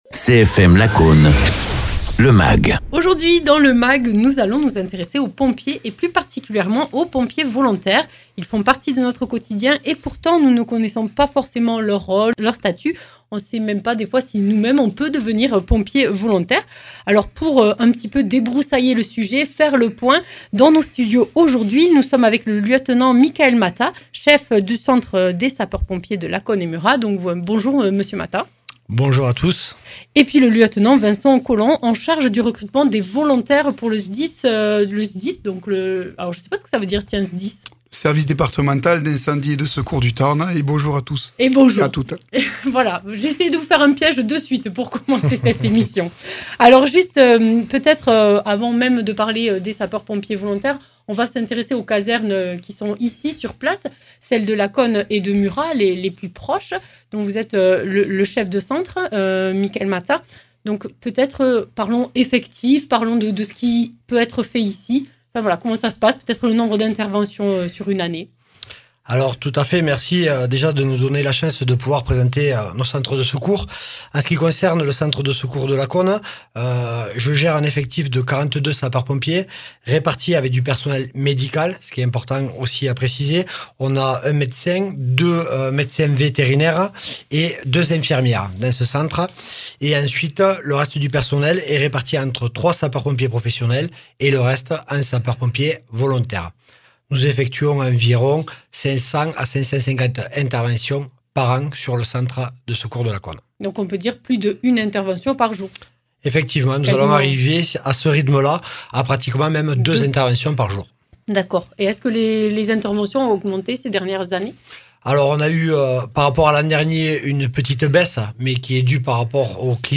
Les pompiers volontaires font partie de notre quotidien et pourtant nous ne connaissons pas forcément leur rôle, leur statut et nous ne savons pas qui peut prétendre devenir sapeurs pompiers volontaire. Nous faisons un point avec nos invités.
Interviews